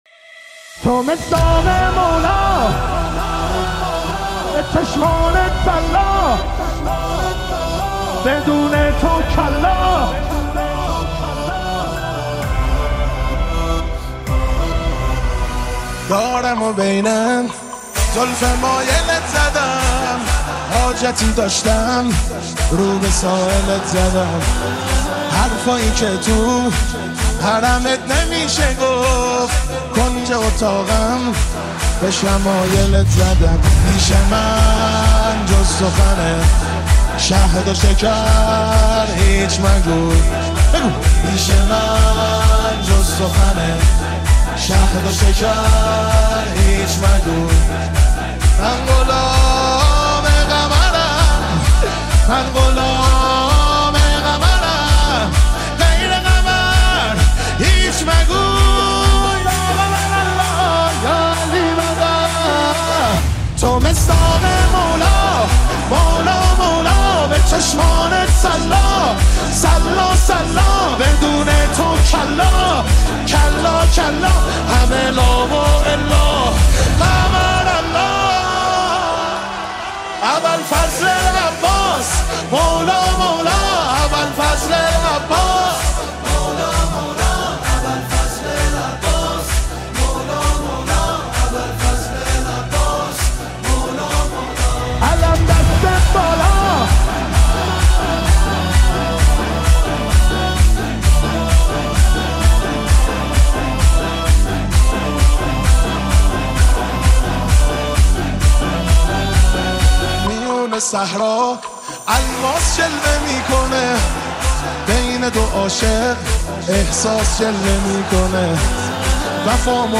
مداحی حضرت اباالفضل